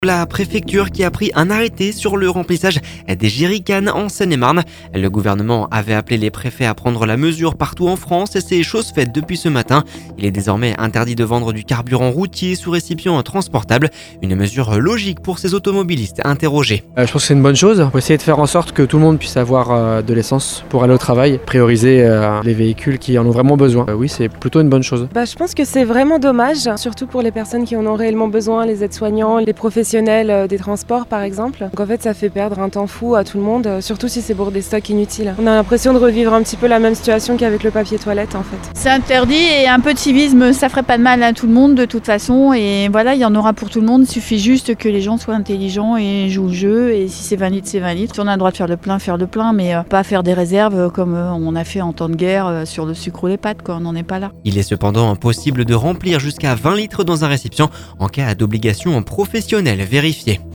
Il est désormais interdit de vendre du carburant routiers sous récipient transportable, il est cependant possible de remplir jusqu’à 20 litres dans un récipient en cas d’obligation professionnelle vérifiée ! Une mesure logique pour ces automobilistes intérogés…